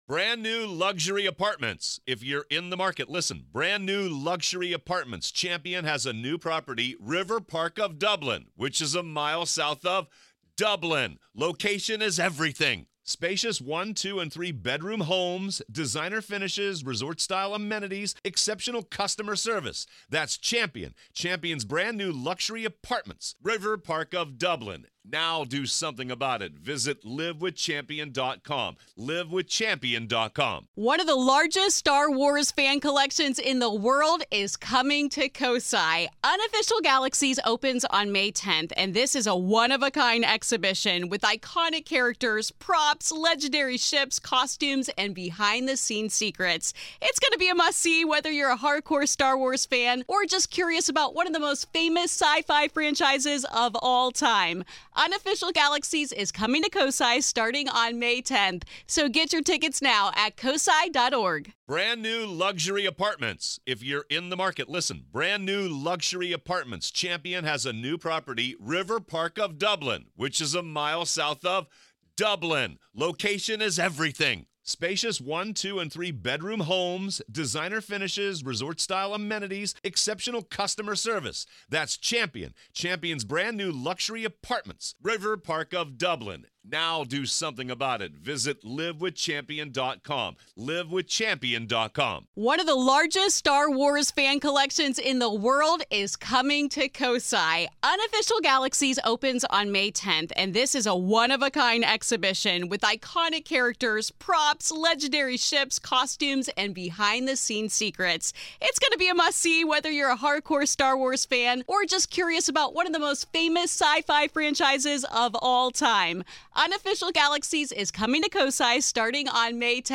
This isn’t just about ghosts—it’s about survival, resilience, and the power of finding hope in unexpected places. This is Part Two of our conversation.